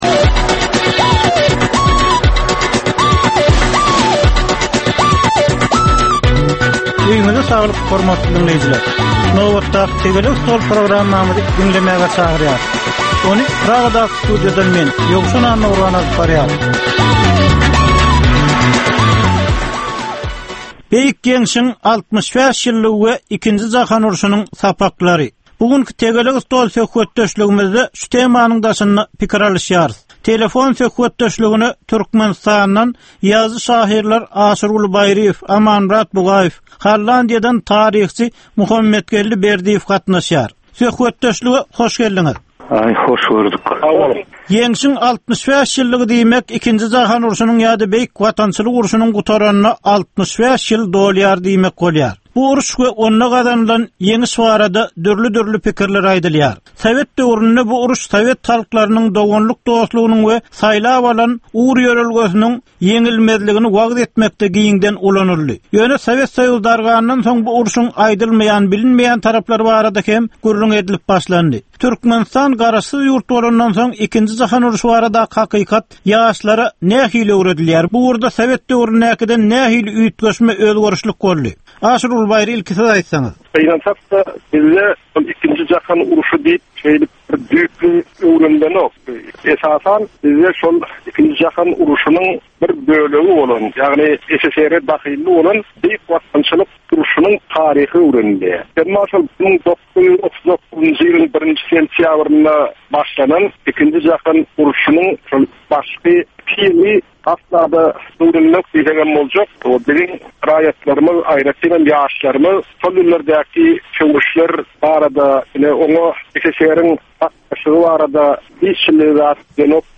Jemgyýetçilik durmuşynda bolan ýa-da bolup duran soňky möhum wakalara ýa-da problemalara bagyşlanylyp taýýarlanylýan ýörite diskussiýa. 30 minutlyk bu gepleşikde syýasatçylar, analitikler we synçylar anyk meseleler boýunça öz garaýyşlaryny we tekliplerini orta atýarlar.